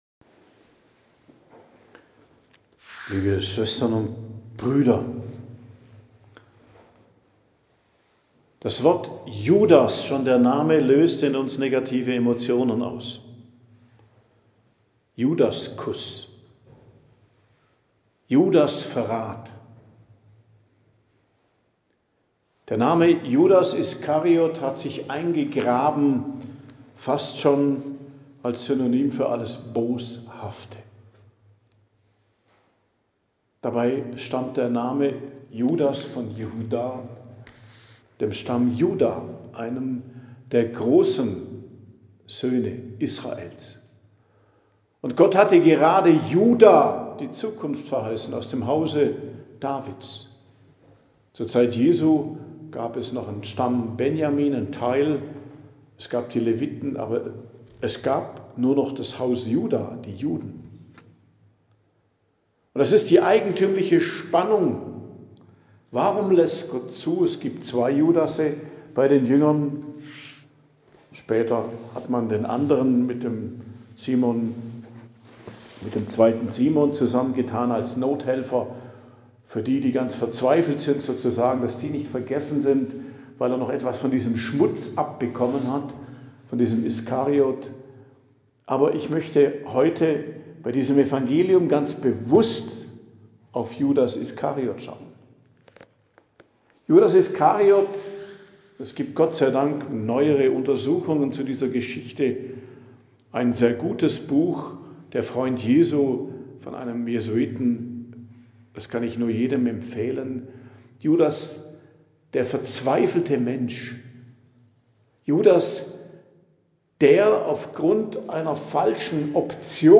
Predigt am Dienstag der Karwoche, 31.03.2026 ~ Geistliches Zentrum Kloster Heiligkreuztal Podcast